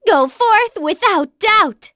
Artemia-Vox_Victory.wav